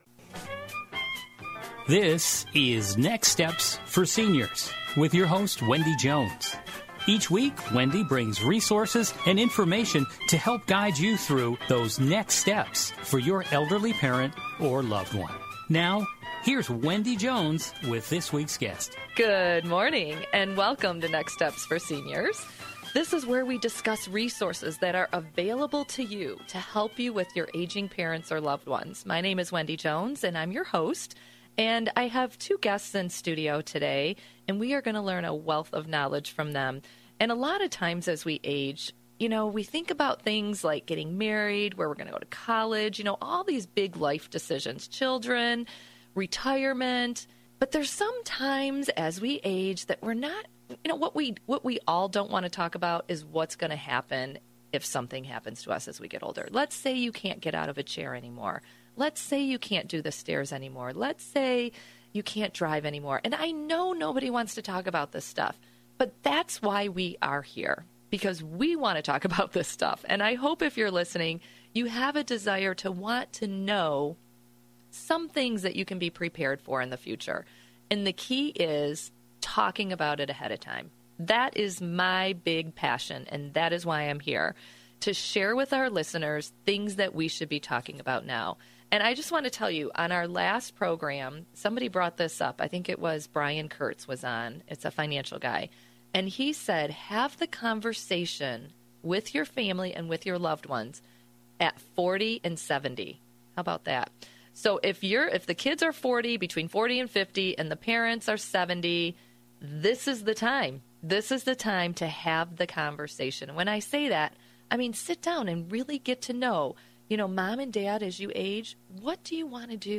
RadioInterview.mp3